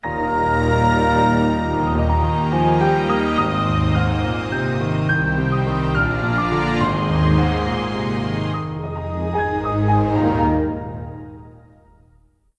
Windows NT XP Startup.wav